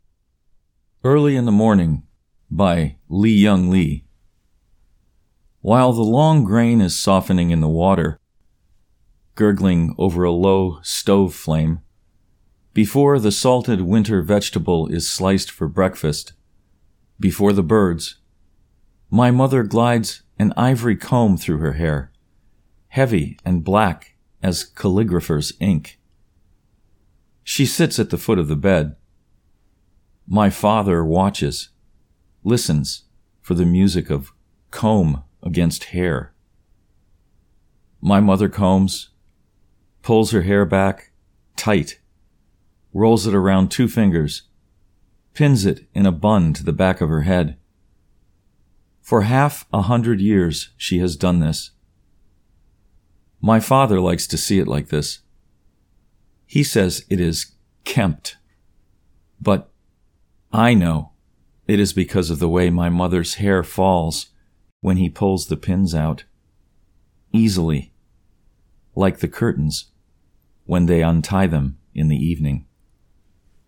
Early in the Morning by Li-Young Lee © (Recitation)